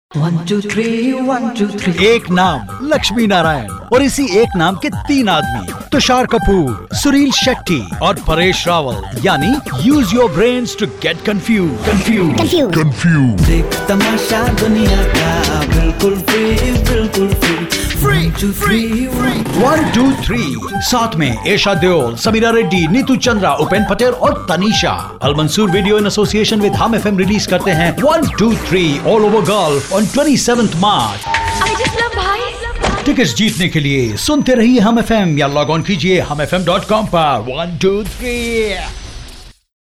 Hindi, Urdu, Indian English, friendly, warm, sexy, dramatic, expressive, versatile, can traslate into Hindi and Urdu, can write concept & scripts, can mix audio also
Sprechprobe: Sonstiges (Muttersprache):
Voice adaptable to any genre.